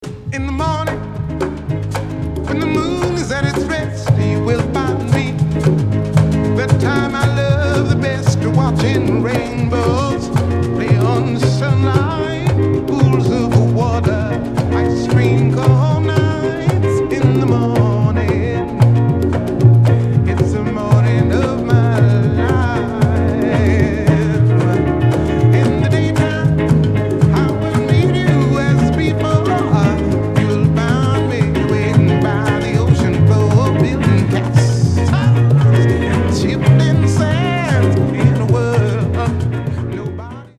Vocals and piano
Recorded on April 3, 1969 at a TV show in Munich, Germany
The arrangements are rhythmically adventurous.